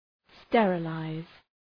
Προφορά
{‘sterə,laız}